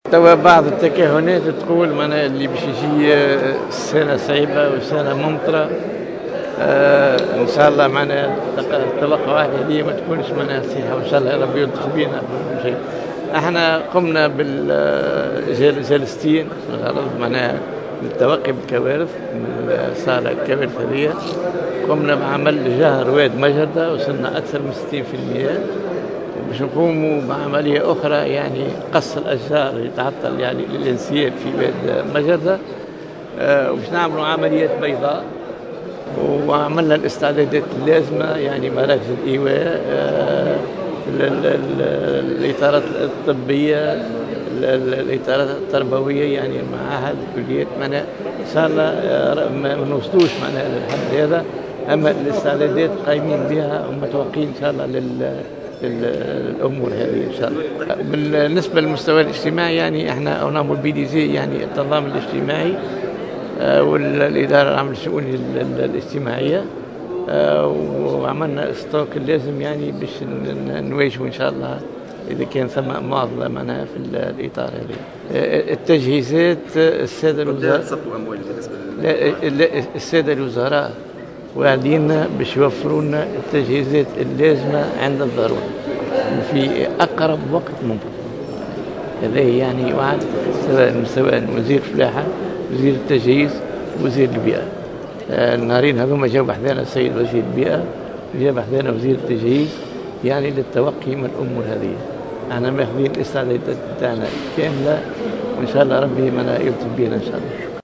والي جندوبة يتحدث عن استعدادات الجهة لمجابهة موسم الامطار